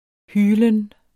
Udtale [ ˈhyːlən ]